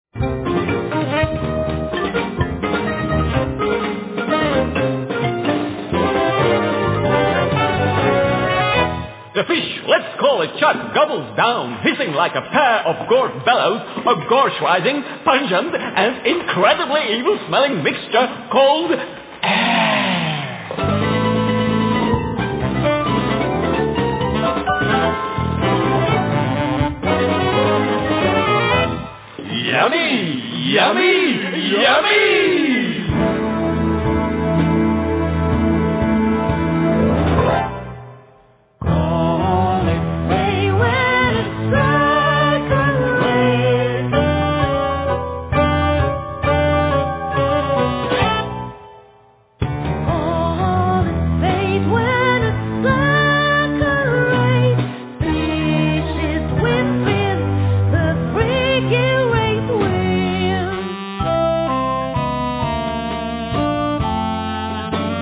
Vocals
Soprano+Tenor Sax
Yamaha Grantouch(Piano)
Marimba,Korg 01W,Programming & conducting
5-string e.bass
Drums